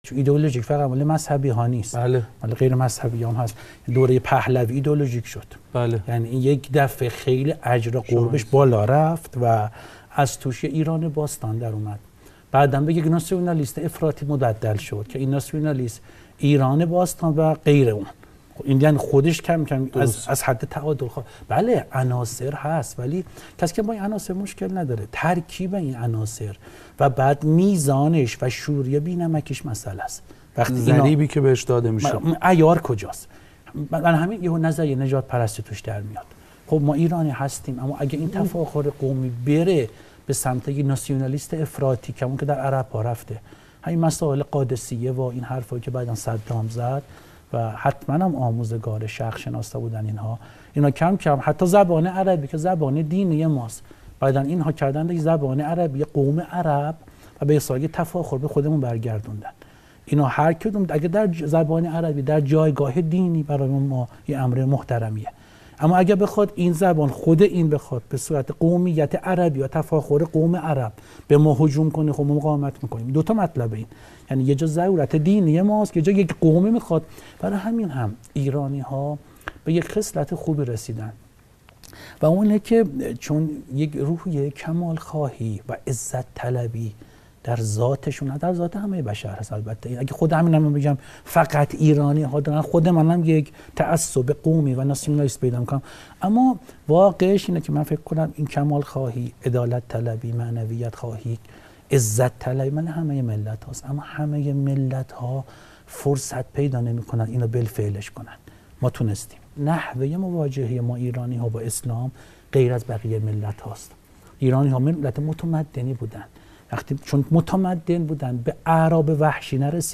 بخشی ازمناظره دکترموسی نجفی ودکترزیباکلام دربرنامه 180 درجه شبکه افق سیما درتاریخ 31 شهریورماه 1396